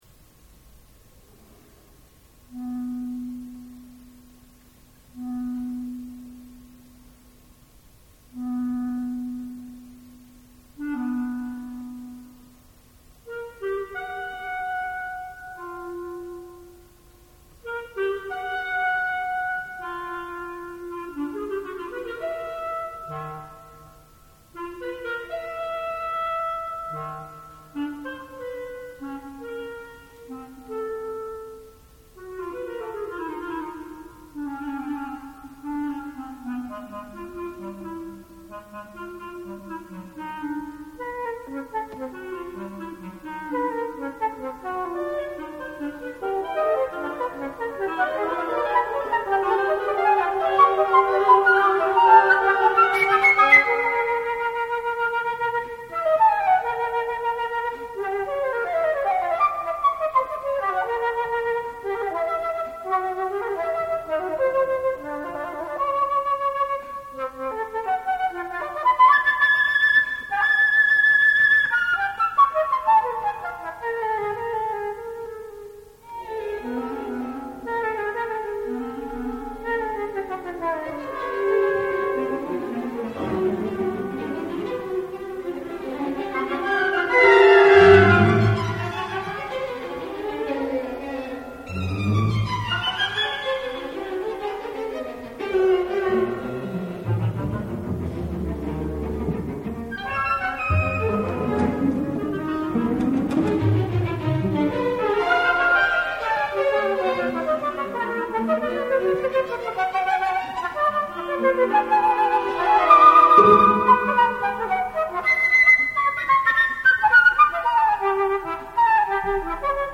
16/4/1994 "live"